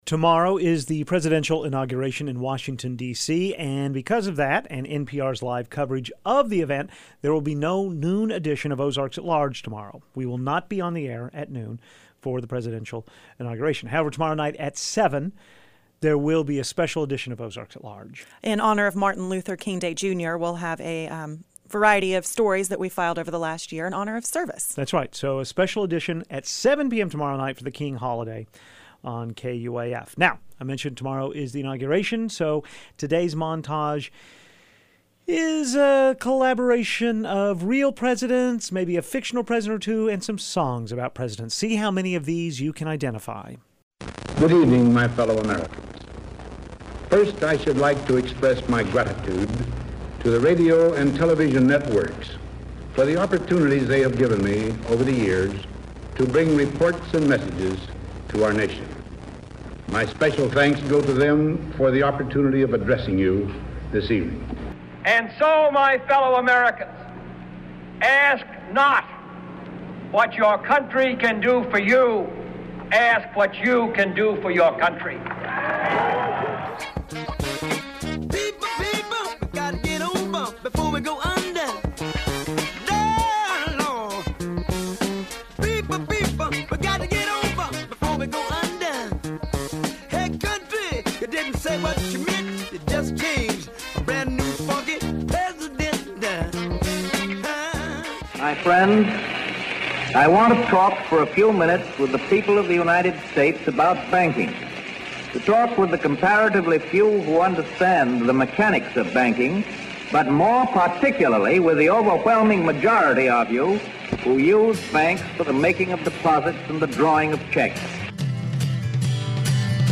Here's the list of our presidential songs, quotes and miscellany for our montage: President Dwight Eisenhower's farewell address on television on January 17, 1961 President John F. Kennedy's inaugural address three days later. James Brown sings "Funky President."
Johnny Cash performs the standard "Mr. Garfield."